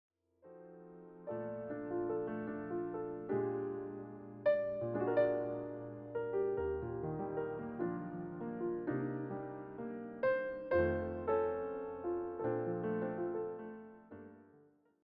all arranged and performed as solo piano pieces.